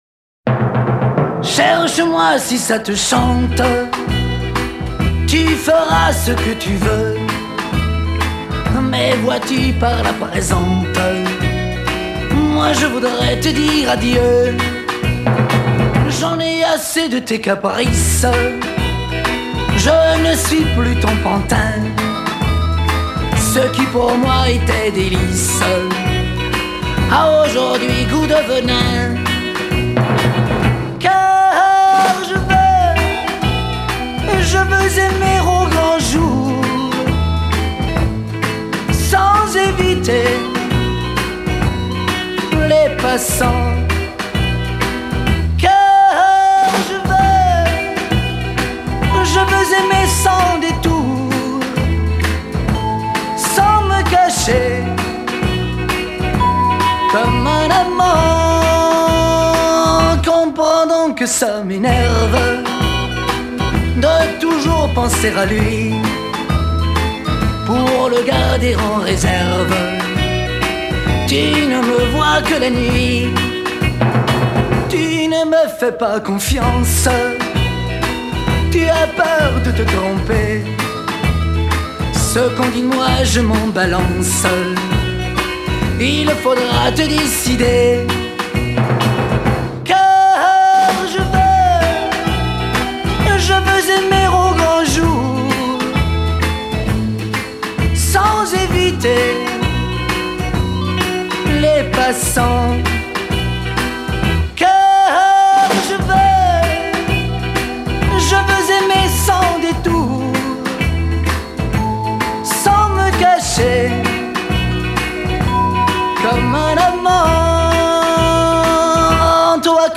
Genre:Pop
Style:Chanson